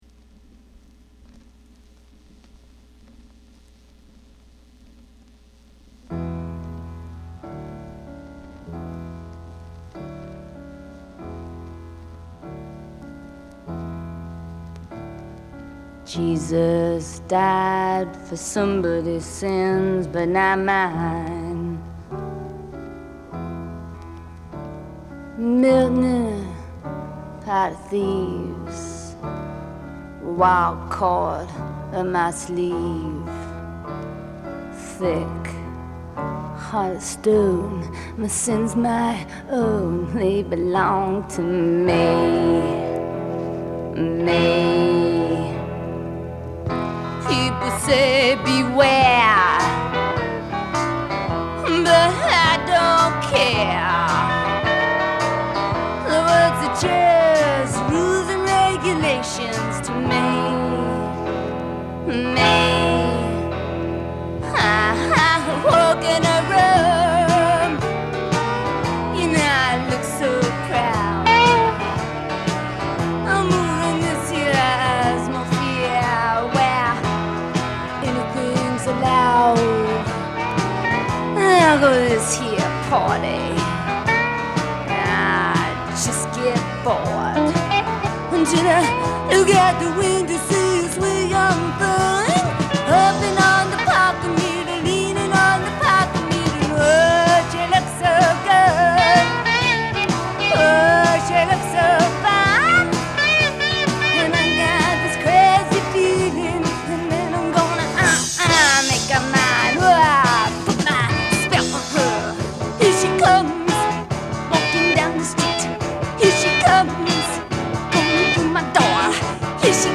ROCK / PUNK
盤は薄い擦れや僅かですが音に影響がある傷がいくつかある、使用感が感じられる状態です。